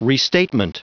Prononciation du mot restatement en anglais (fichier audio)
Prononciation du mot : restatement